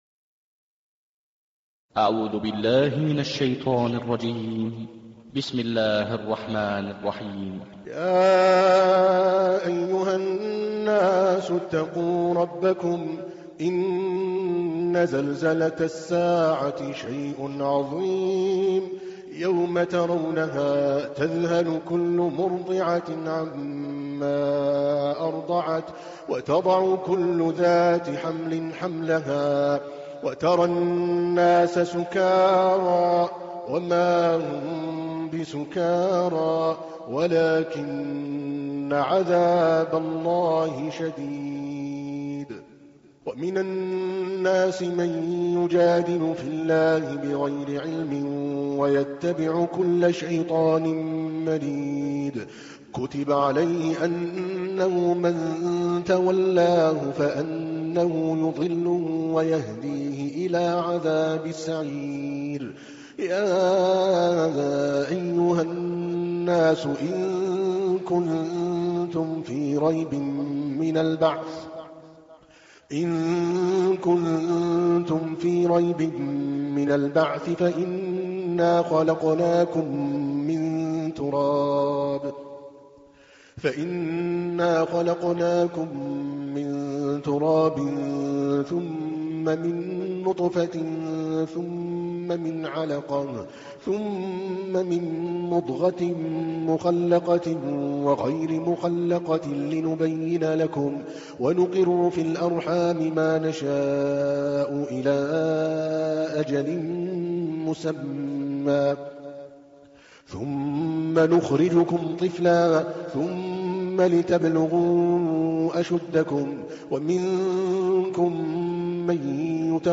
تحميل : 22. سورة الحج / القارئ عادل الكلباني / القرآن الكريم / موقع يا حسين